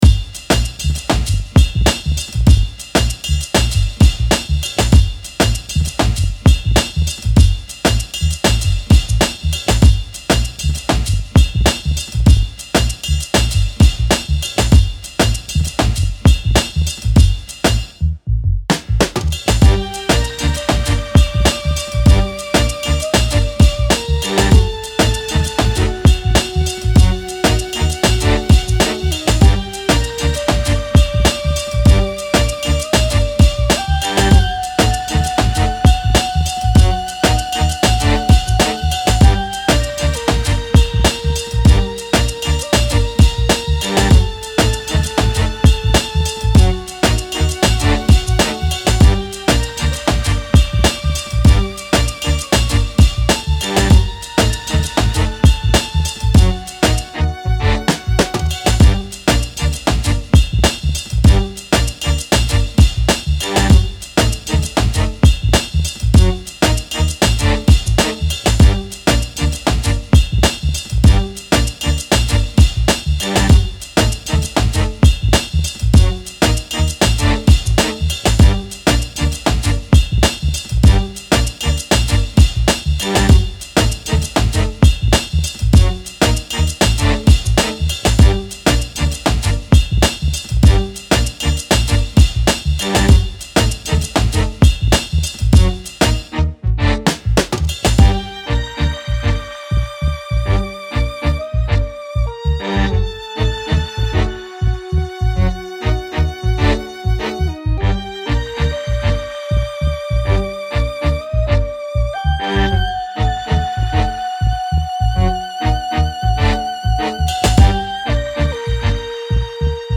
Funky street beat with attitude.